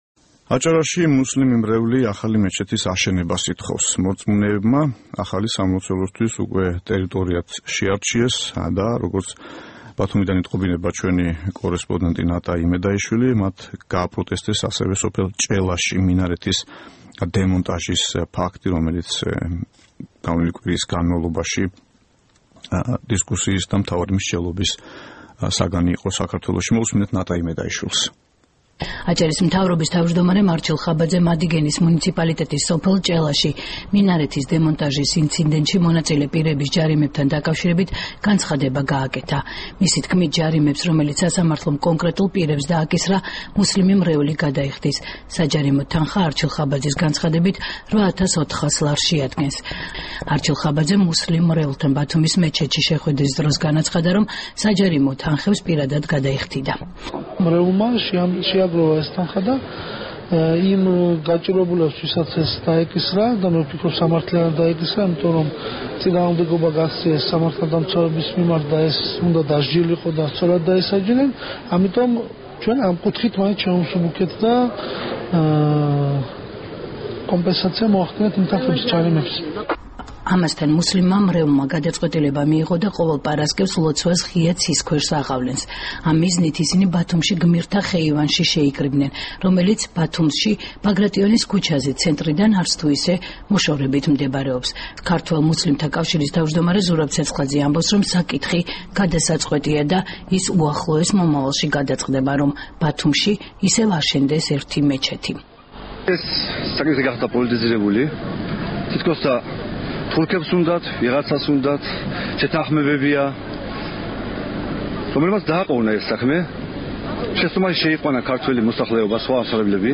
რეპორტაჟი ბათუმიდან